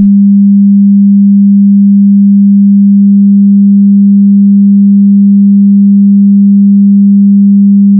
Sine waves are pure bell-like tones and it is easy to hear impurities in them. So I have generated two sine waves; the first is a pure 200Hz tone and the second has 1.0% added second harmonic.
I have combined the two wav files into a single file, the pure sine tone and the other with 1% second harmonic distortion, and you can play them by clicking the image to the left. Remember... this wav file is part pure tone and part distorted.
distortion1.wav